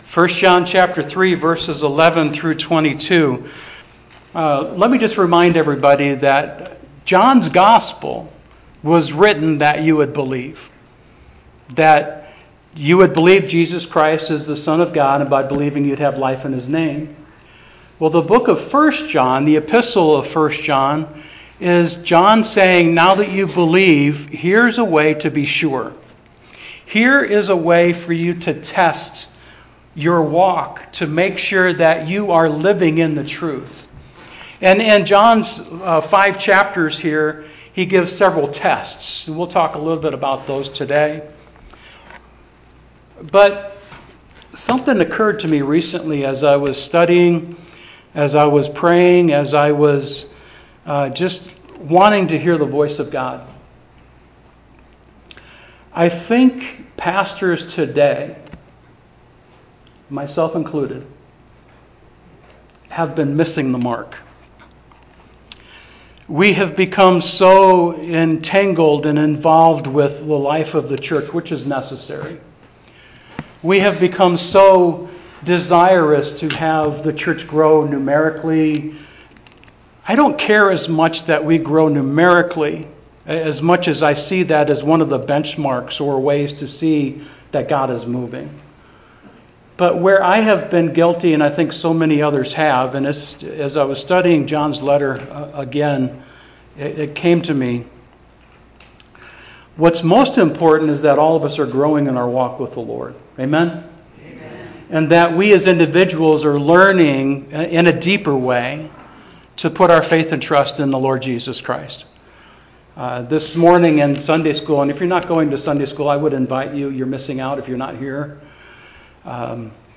Sermon Outline: A Righteous Love Leads to Life (11-12) A Worldly Hate Leads to Death (13-15) A Giving Heart is Driven by Truth (16-18) A Truthful Heart is Pleasing to God (19-22) SHARE ON Twitter Facebook Buffer LinkedIn Pin It